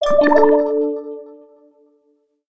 09581 bubble message ding
blurp bubble ding message notification pop water wet sound effect free sound royalty free Sound Effects